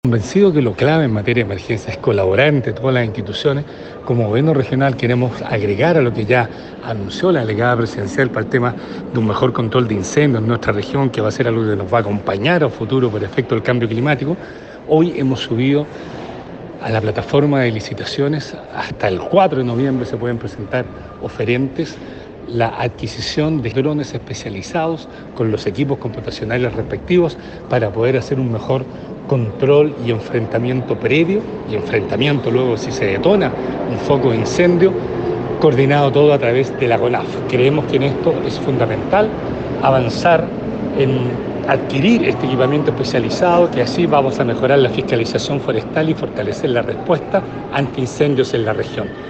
El Gobernador Regional de Los Lagos, Patricio Vallespin, enfatizó en que lo clave en materia de emergencia es colaborar entre todas las instituciones, para el tema de un mejor control de incendios en nuestra región, que va a ser algo que nos va a acompañar a futuro por el efecto del cambio climático.
16-octubre-24-patricio-vallespin-equipos.mp3